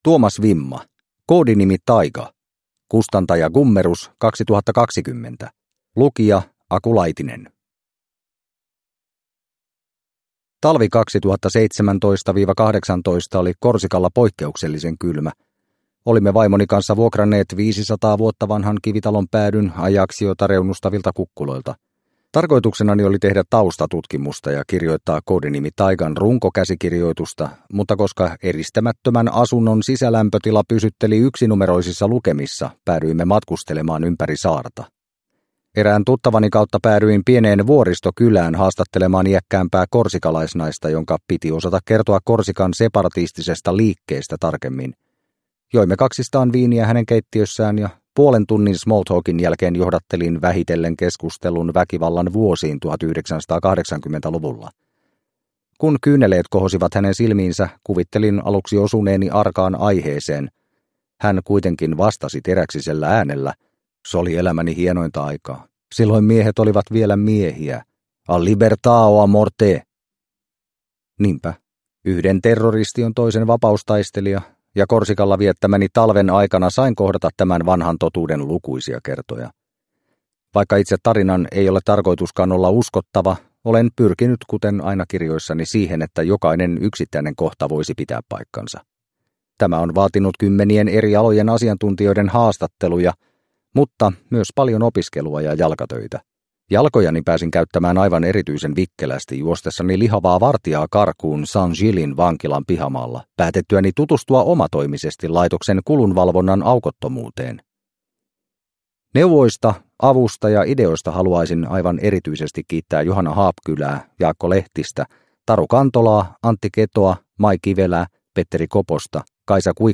Koodinimi Taïga – Ljudbok – Laddas ner